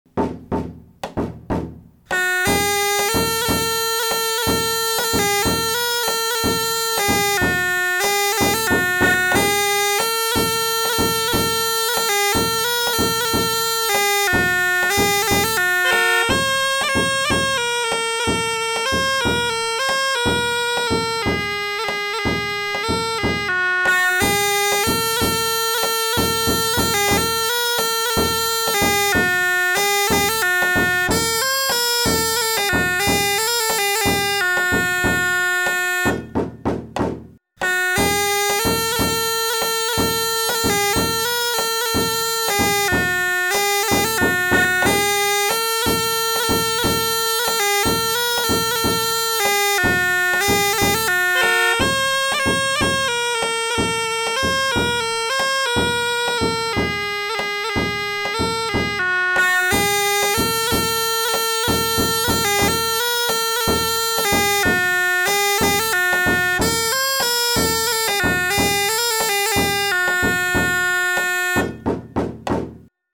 Morceau pour illustration de l'article sur la pibole de Pouzauges
Pièce musicale inédite